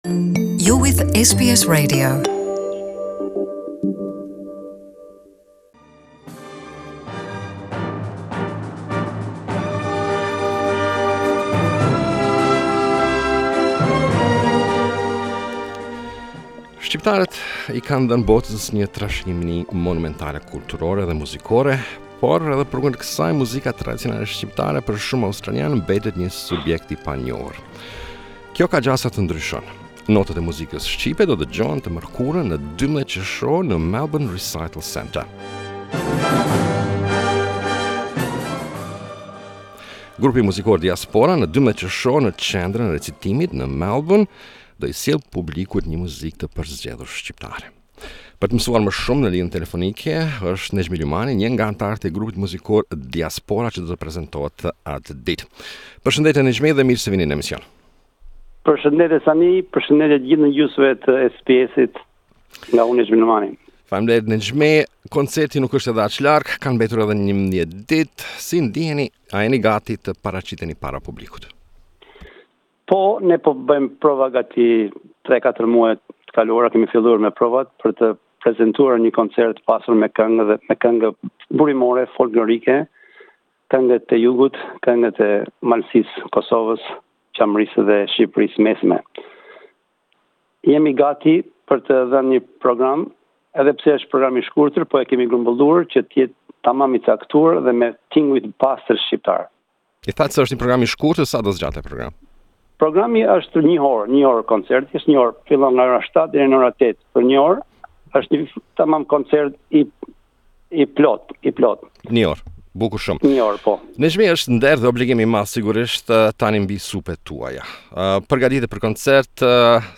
The "Diaspora" music group on June 12 at the Melbourne Recitation Centre will bring to public a select Albanian music. To learn more about this concert we interviewed